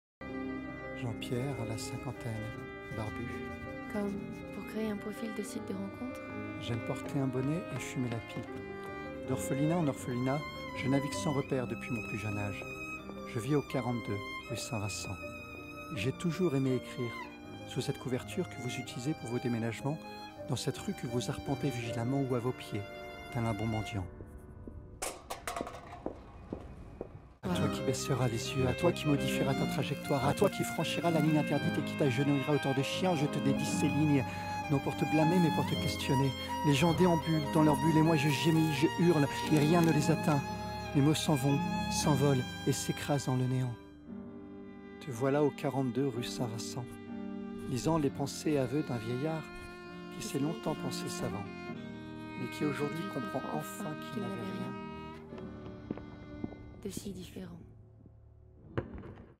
Voix off
25 - 100 ans - Baryton